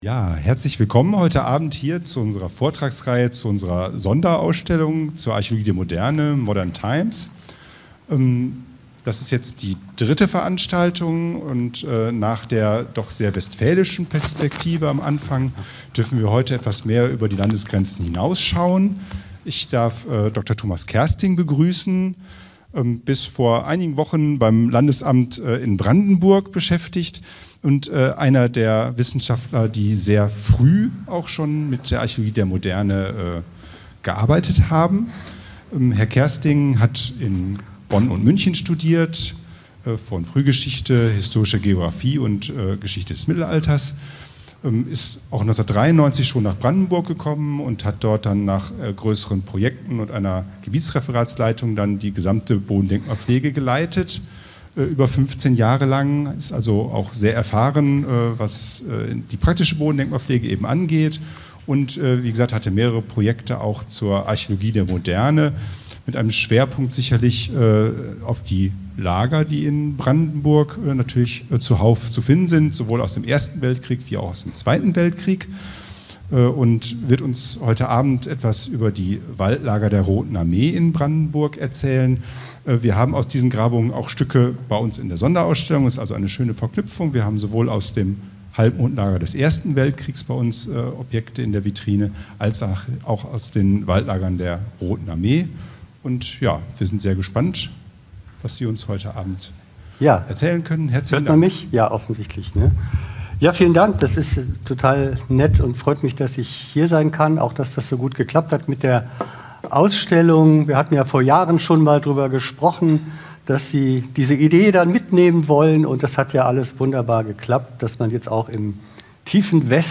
Öffentlicher Vortrag
November 2023 im LWL-Museum für Archäologie und Kultur in Herne zu den Waldlagern der Roten Armee in Brandenburg Nach der Kapitulation Deutschlands im Mai 1945 befanden sich Hunderttausende Rotarmisten westlich der Oder. Die Archäologie erforscht jetzt ihre Unterkünfte in den Brandenburger Wäldern, mit Funden, die vom Alltag zwischen Krieg und Frieden, Triumph und Not, Propaganda und Realität erzählen – aber auch von Befreiung und erneuter Bedrängnis.